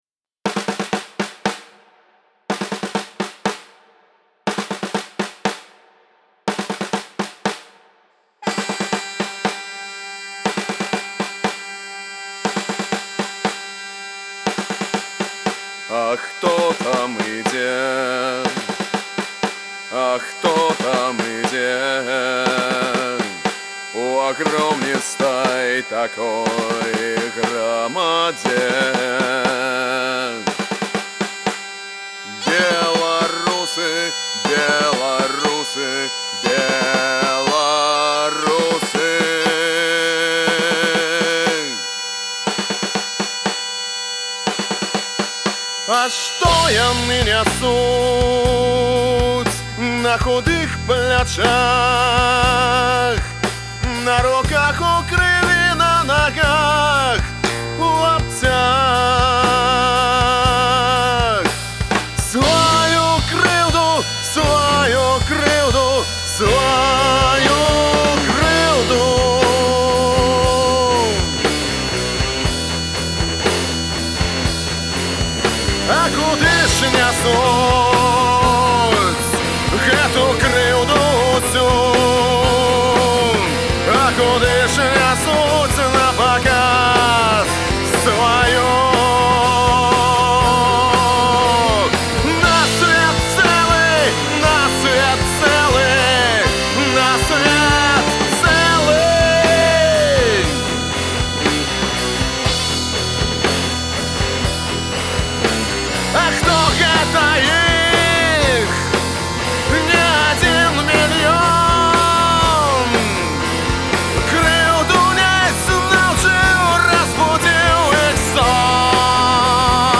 Фольк-панк гурт заснаваны ў 2012 ў Горадні.